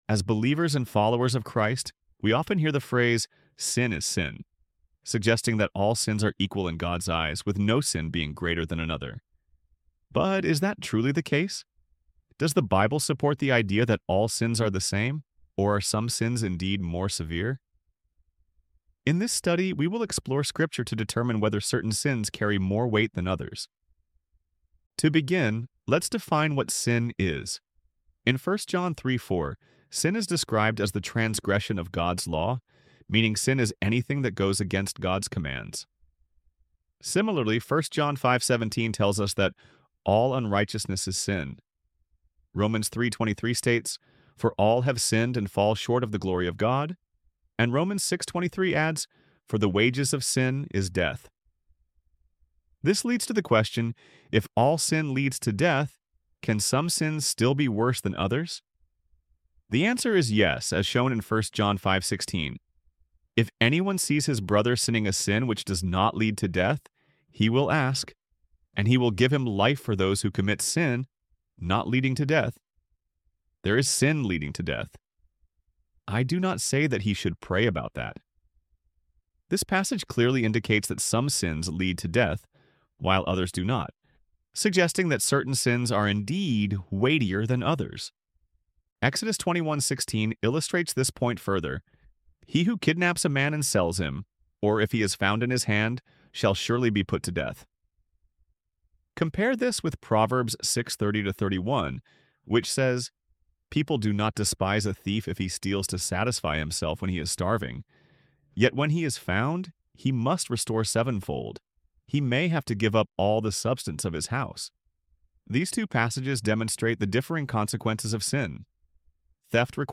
ElevenLabs_Bible_Study_on_Isaiah_55_1-7.-1.mp3